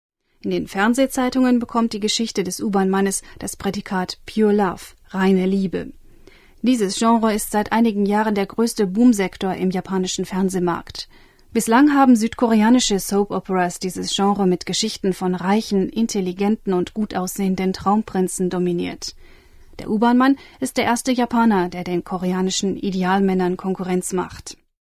ドイツ語ナレーション 1